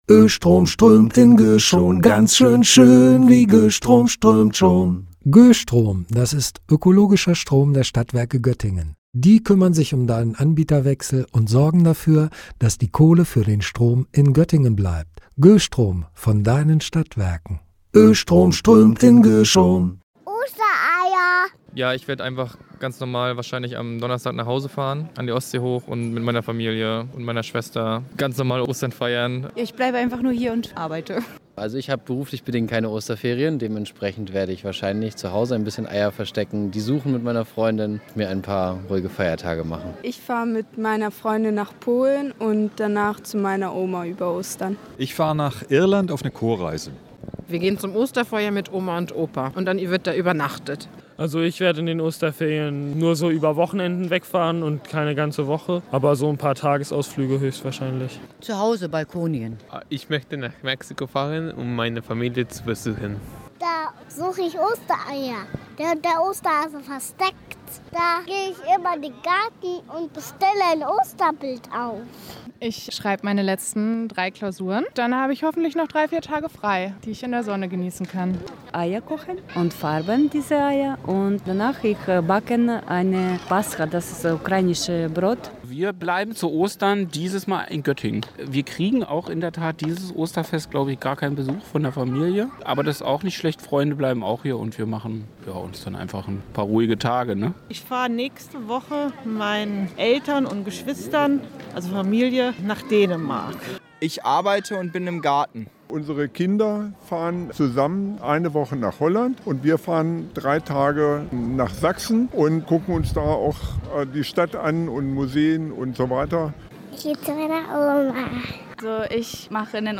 Umfrage